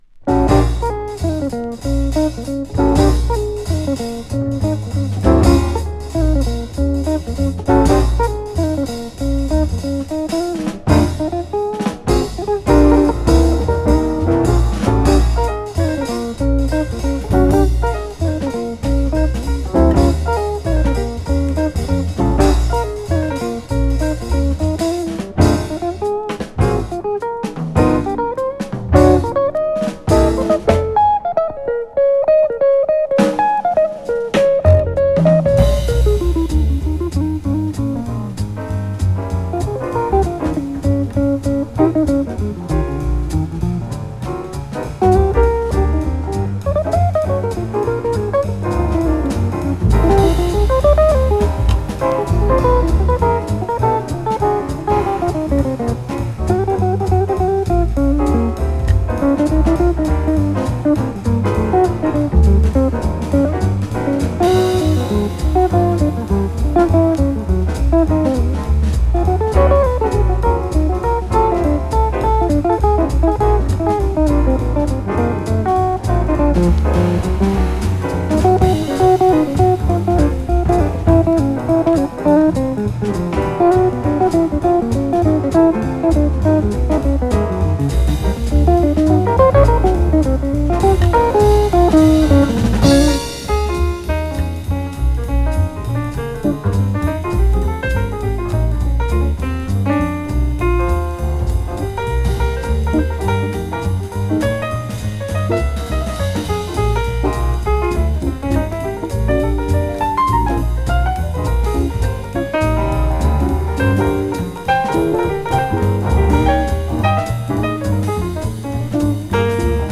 スウィンギンな